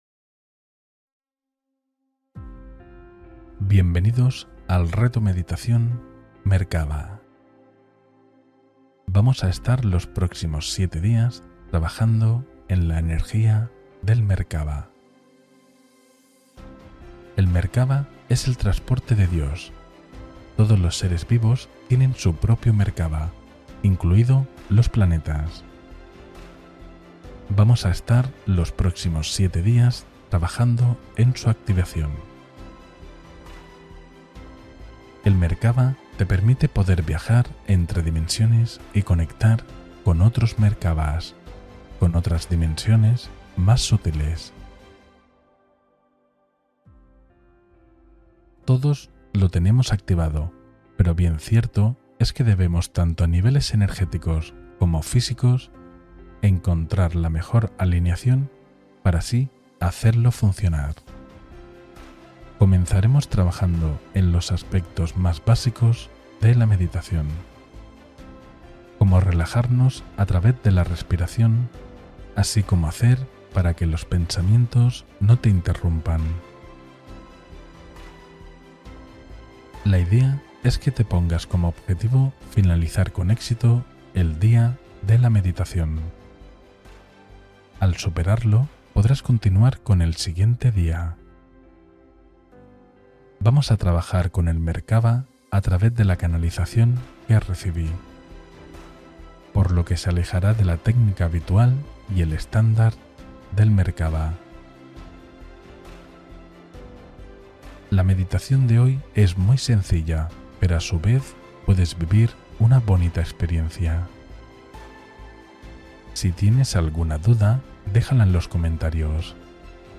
Activación Inicial del Campo Energético: Meditación Merkaba de Enraizamiento